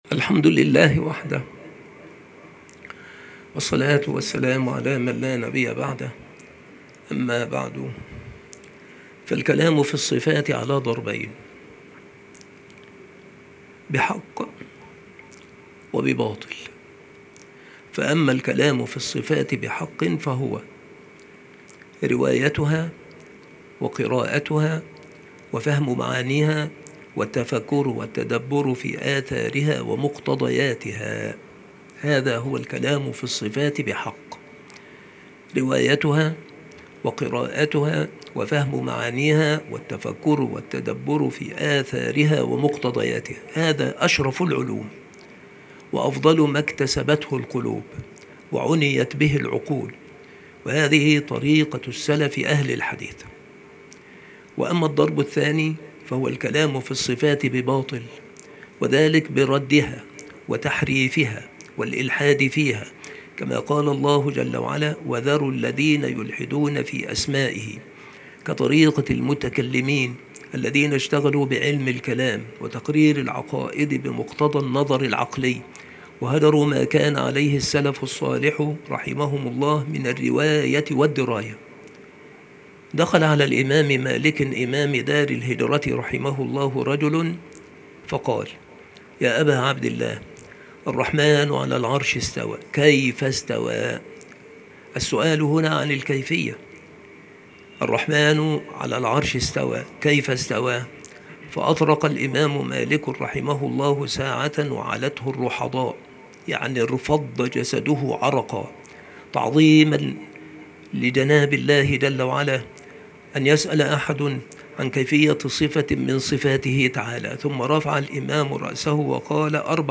المحاضرة
مكان إلقاء هذه المحاضرة بالمسجد الشرقي - سبك الأحد - أشمون - محافظة المنوفية - مصر